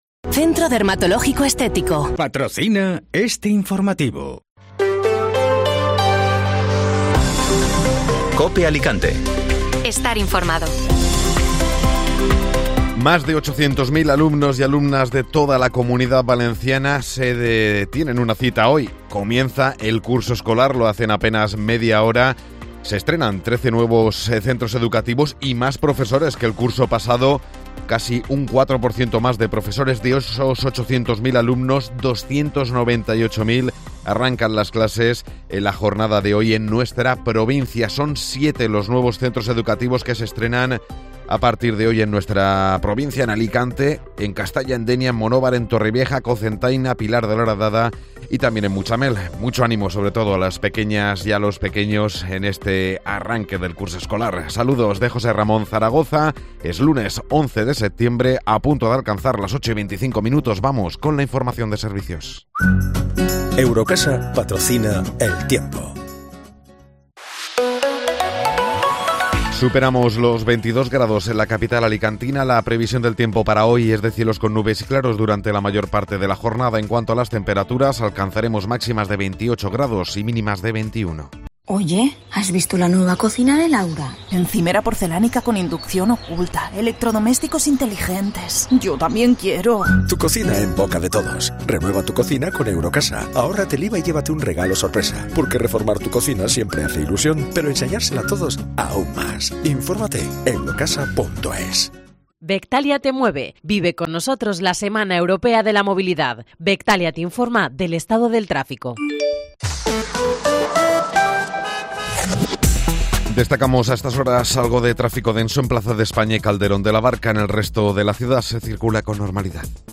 Informativo Matinal (Lunes 11 de Septiembre)